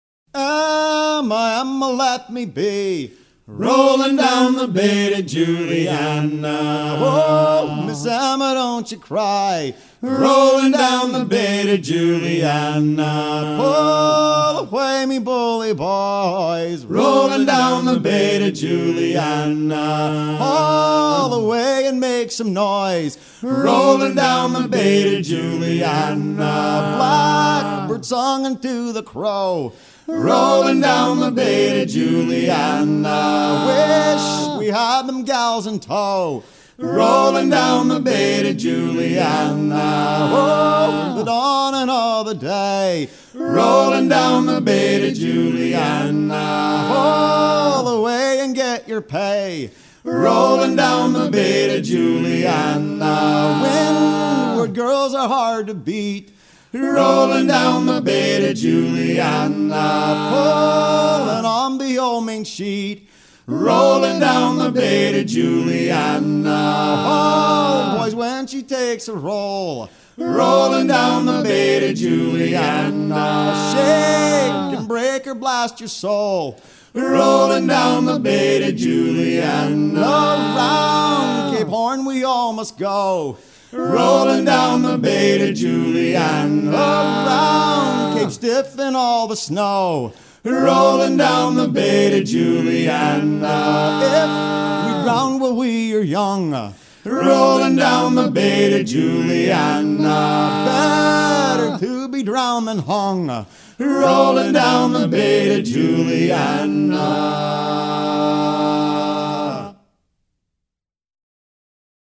à hisser main sur main
Pièce musicale éditée